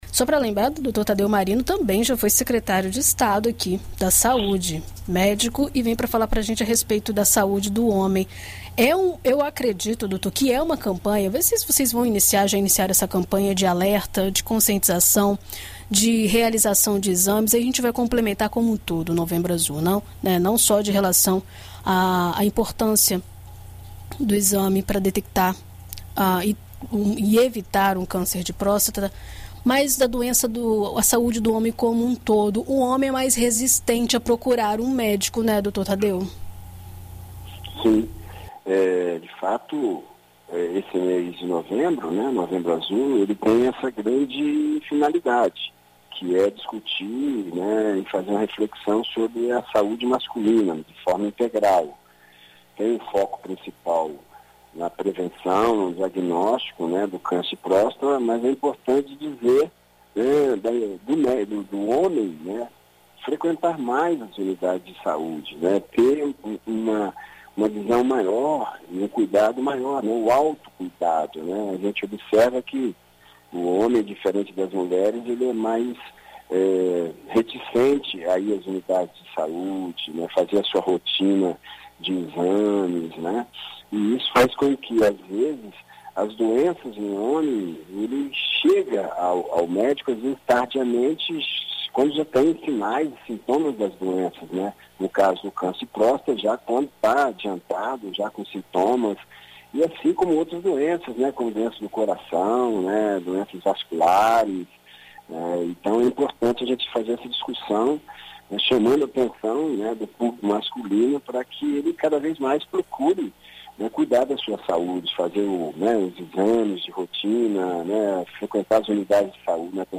Em entrevista à BandNews FM Espírito Santo nesta quarta-feira (03), o subsecretário de Estado de Planejamento e Transparência da Saúde, Tadeu Marino, fala sobre a campanha Novembro Azul e a importância dos cuidados com a saúde do homem.